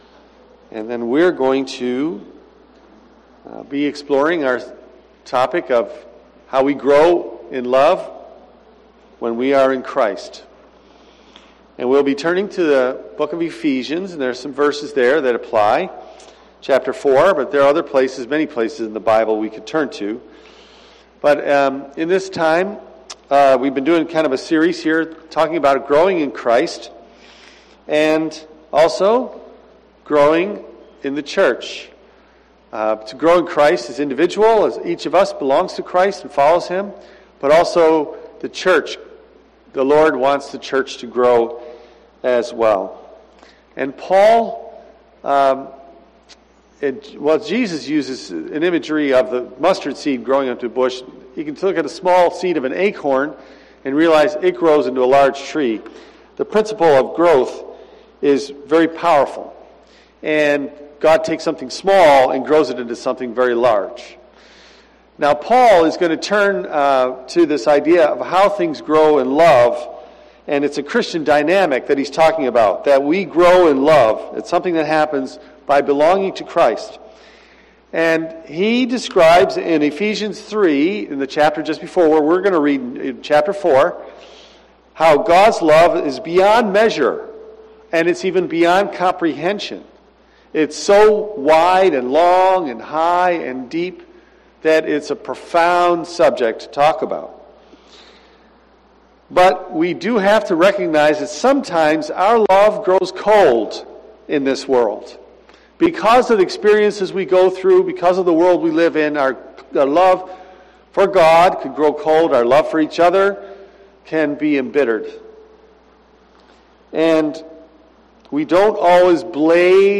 I am going to preach on growth in Christ and growth in the church for the next few weeks. Paul describes how the Christian dynamic is to grow in love. He describes God’s love as beyond measure or comprehension.